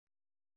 ♪ ṛeppu